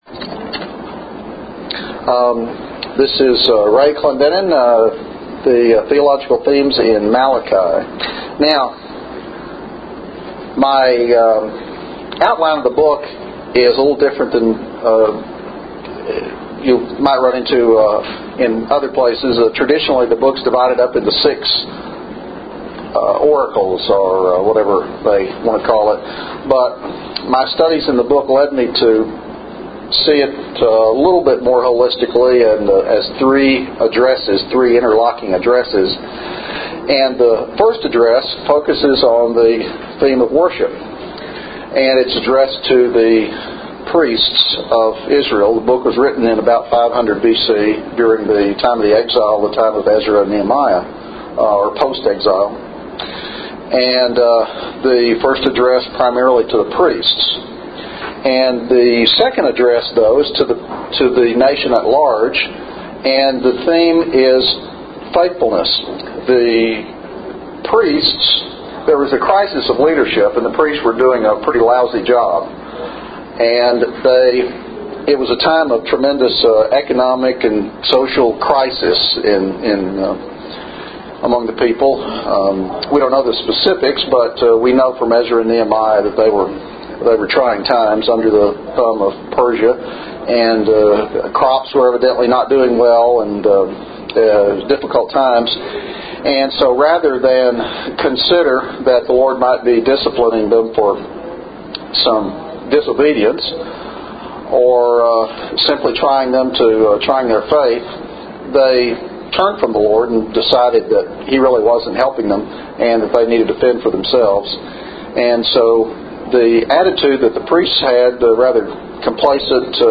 Breakout Session